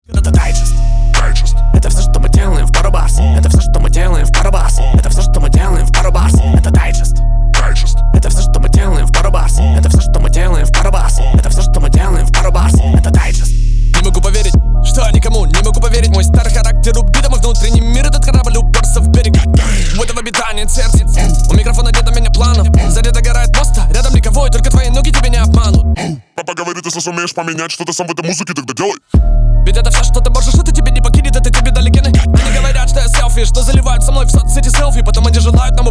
• Качество: 320, Stereo
Хип-хоп
крутые
качающие
речитатив